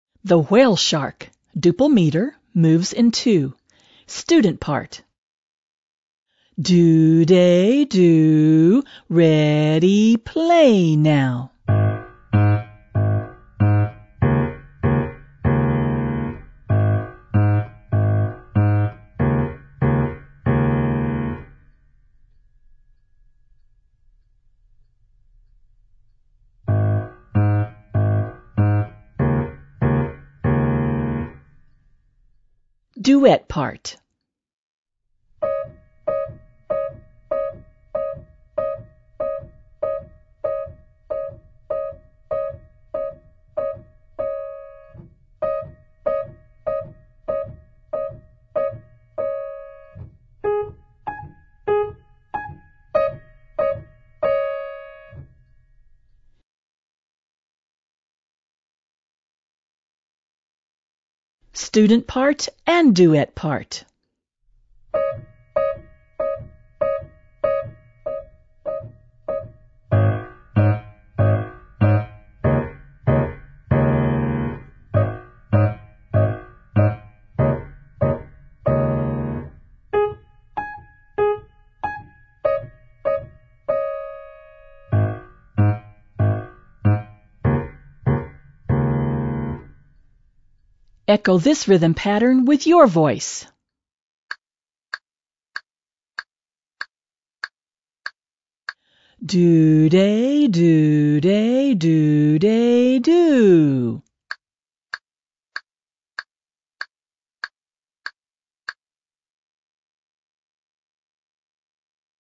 • Dynamics: Loud
• Tempo: Slow
• Meter: Duple (Du-de Du-de)
• Keyboard Register: High
• Hands: Alternating hands